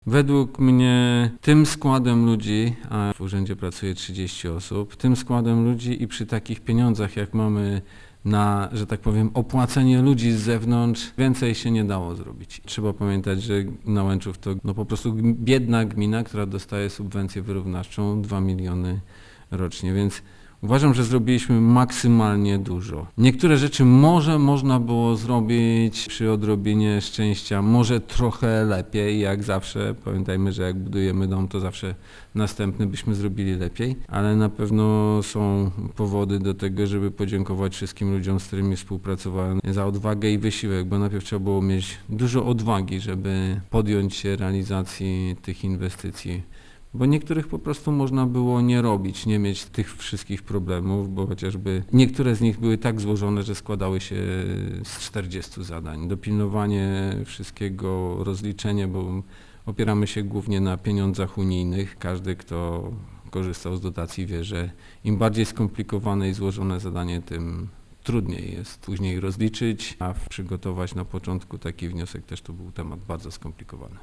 Burmistrz Andrzej Ćwiek uważa, że to znakomity wynik, bo osiągnięty przez gminę wspieraną subwencją wyrównawczą i jednocześnie przez jeden z najtańszych samorządów w Polsce: